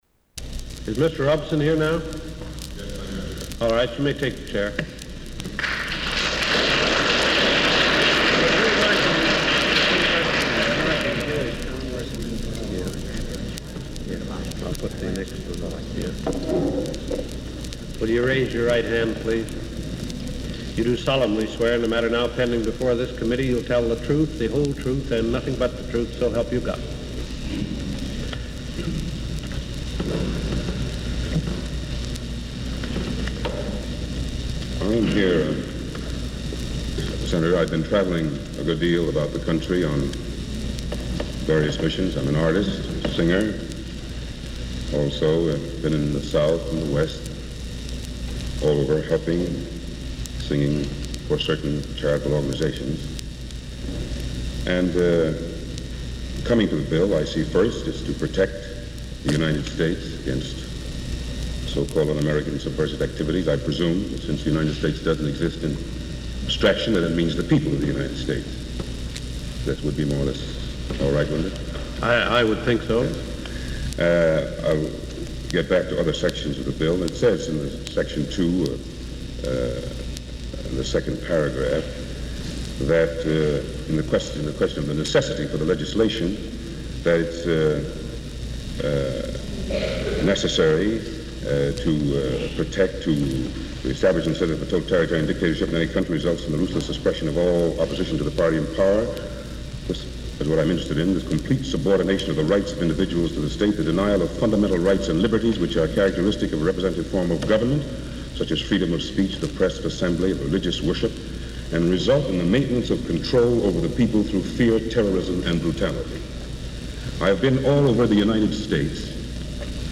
Robeson is questioned by Senator Ferguson and others and he outlines denial of rights to Negroes, discusses the definition of a communist, refuses to reveal his membership, points out absence of prejudice toward Negroes in Russia, and discusses fascism. Robeson implies that he would refuse to adhere to the Mundt bill if passed by Congress.
Broadcast on Washington, WOL, May 31, 1948.